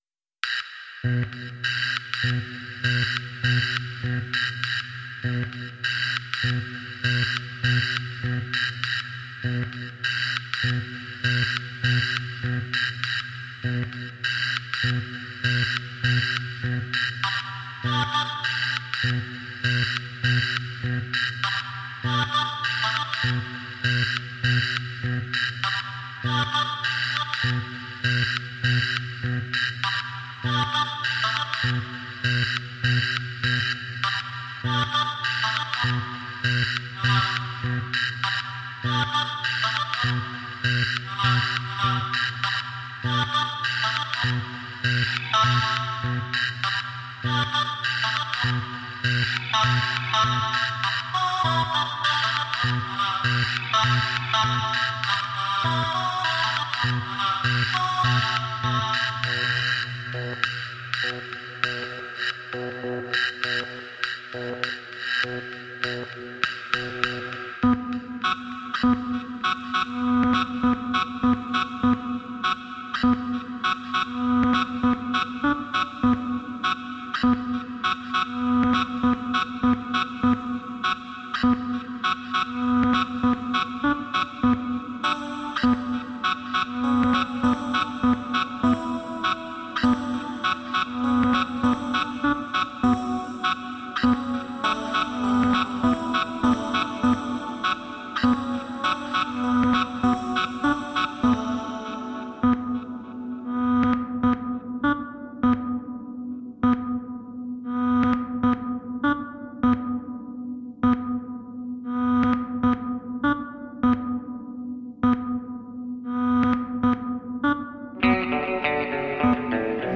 4. Genre: Glitch